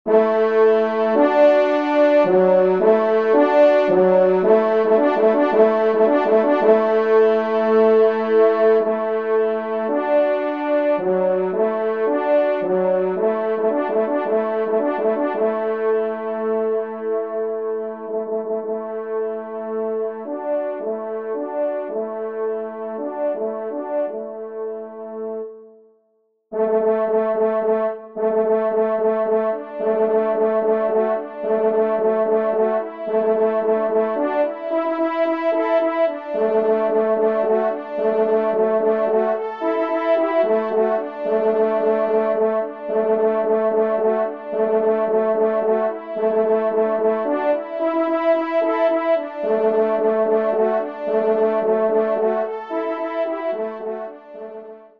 2ème Trompe